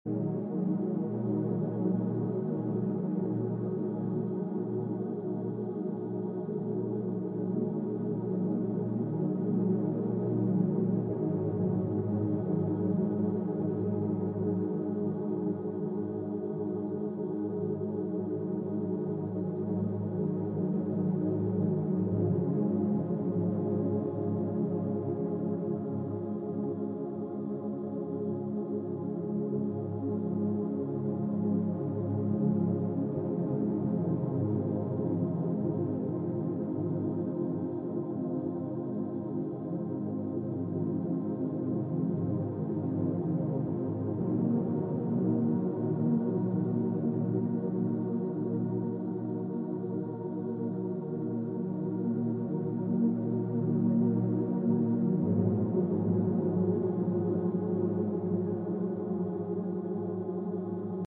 Detox your mind and body with 741 Hz – clear negativity and embrace pure energy!